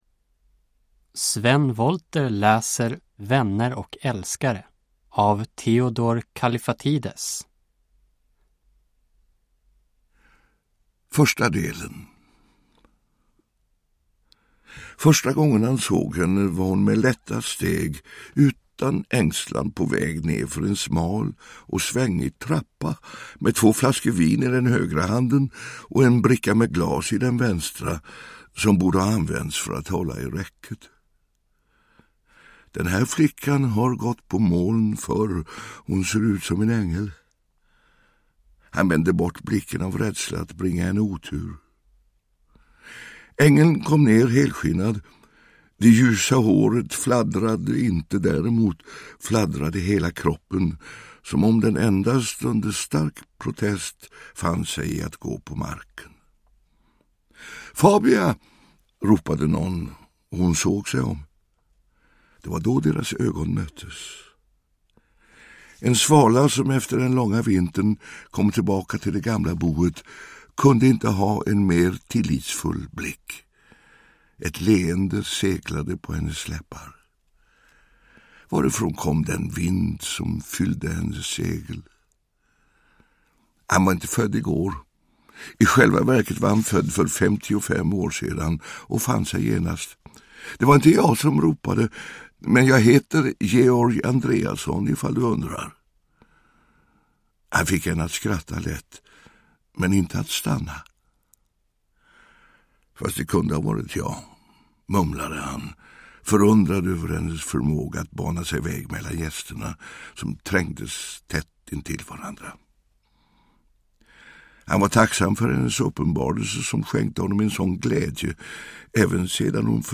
Vänner och älskare (ljudbok) av Theodor Kallifatides